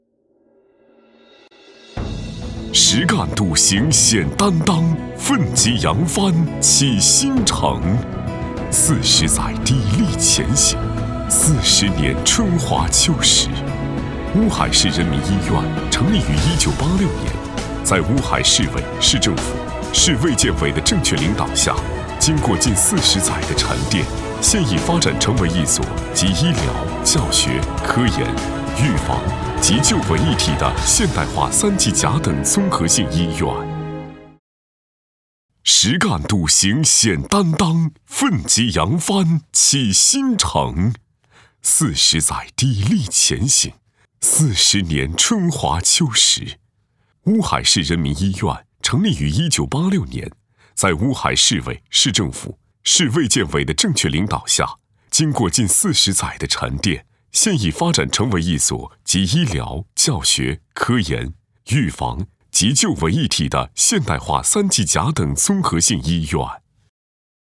特点：科技大气 商务磁性 激情力度
风格:时尚配音
大气专题-实干笃行显担当-试音.mp3